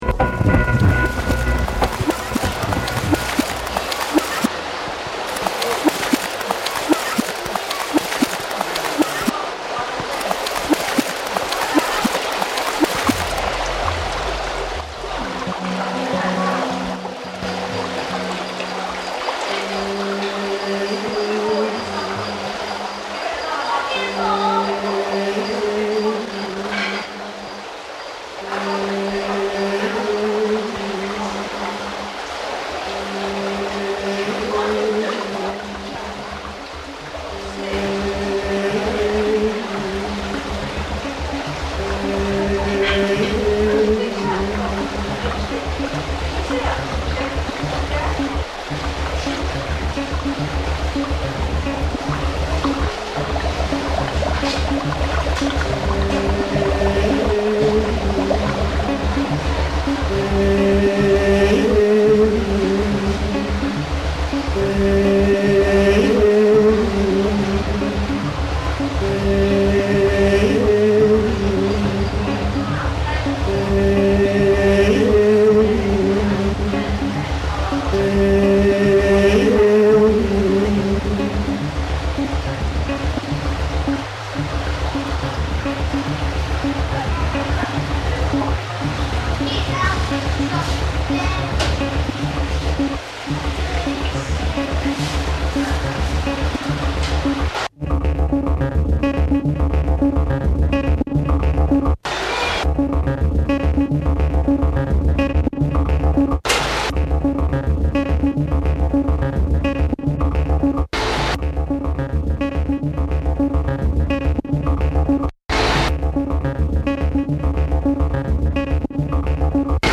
abstract electronic outfit